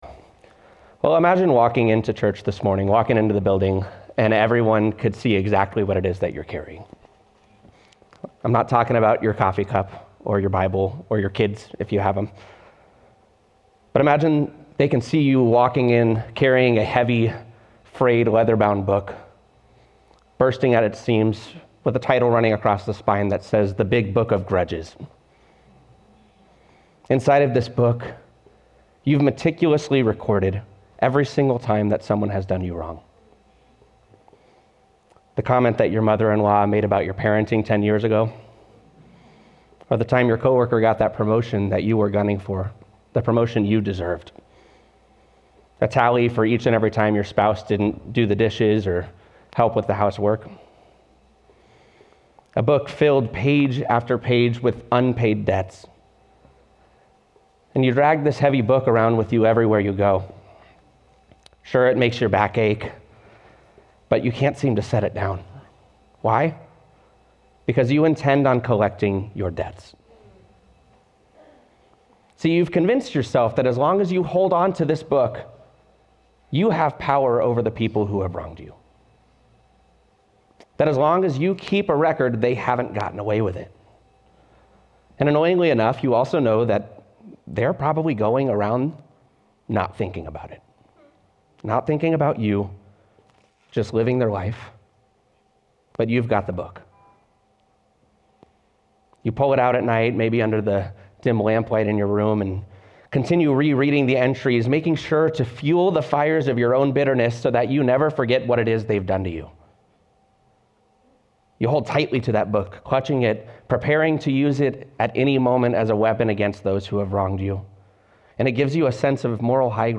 keyboard_arrow_left Sermons / Give It Up Series Download MP3